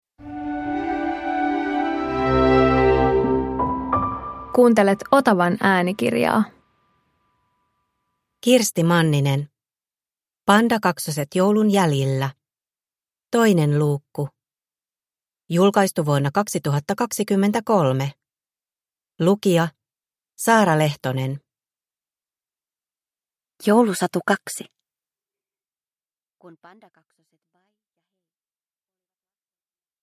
Pandakaksoset joulun jäljillä 2 – Ljudbok